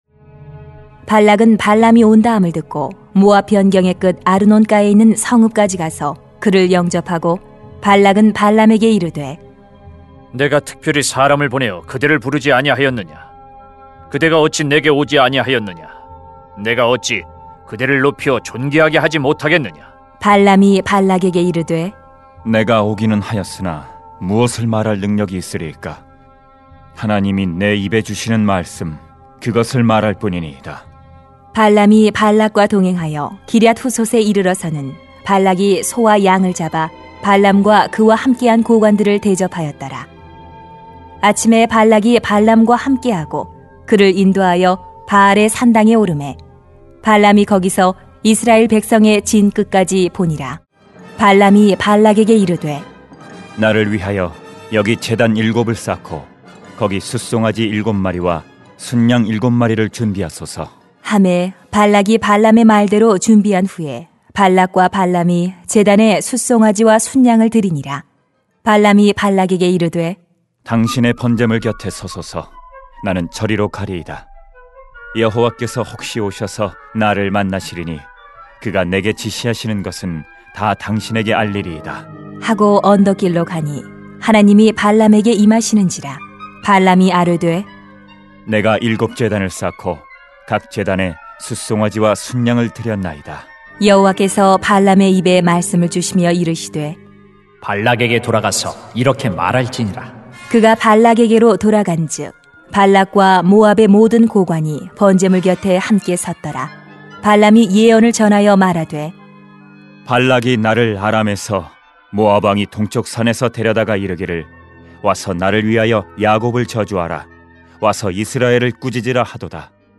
[민 22:36-23:12] 두려워하지 마세요 > 새벽기도회 | 전주제자교회